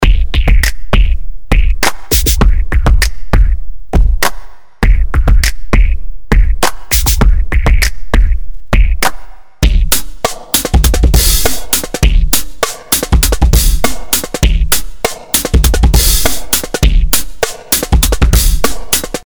Die TB 909 Emulation:
Ich habe zufällig gerade keine TB 909 hier in der Testwerkstatt stehen, würde aber behaupten, dass das Punchbox-Replikat authentisch nach Retro-Analog-Sound klingt: